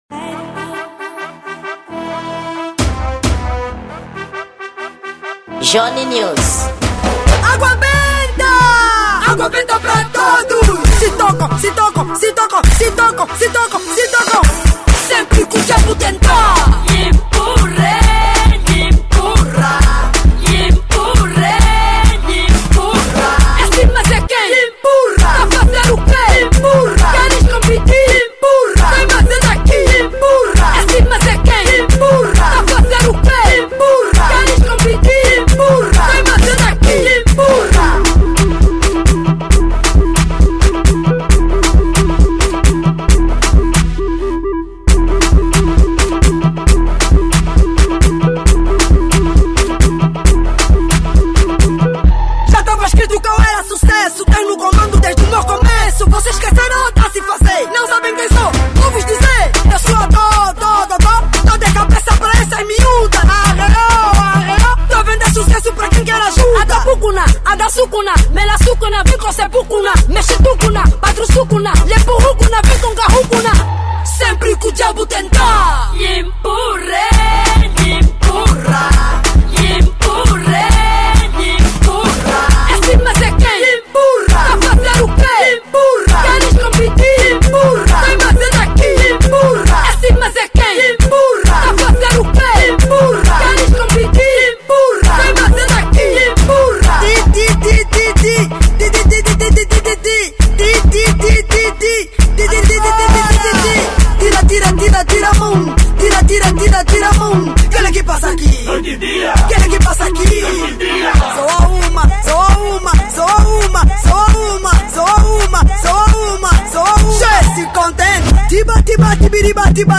Gênero: Kuduro